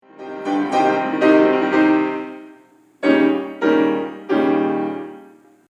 Then, in such a wonderfully typical fashion, he just stops…